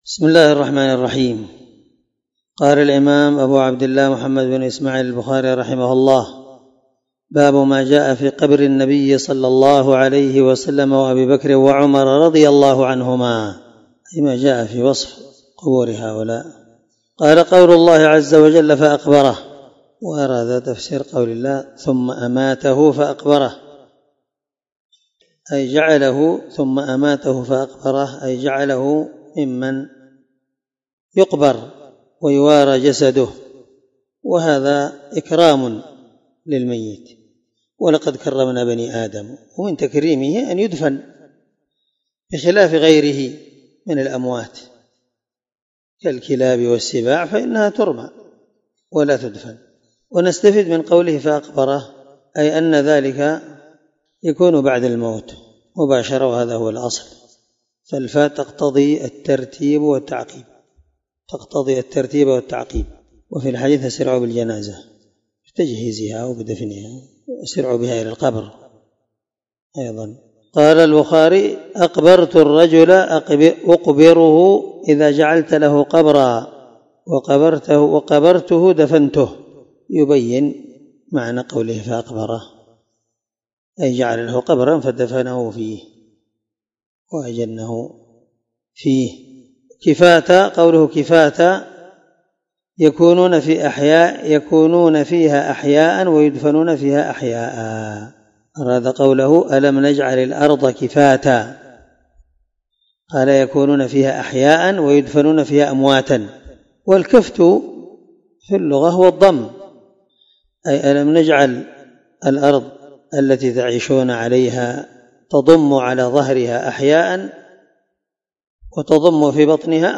799الدرس 72من شرح كتاب الجنائز حديث رقم(1389-1390 )من صحيح البخاري